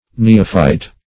Neophyte \Ne"o*phyte\ (n[=e]"[-o]*f[imac]t), n. [L. neophytis,